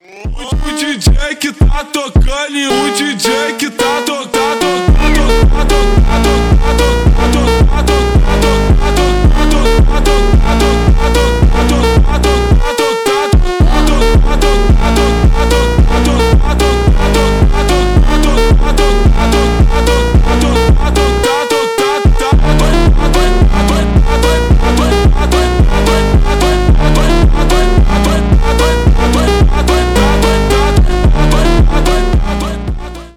bass boosted , бразильский фонк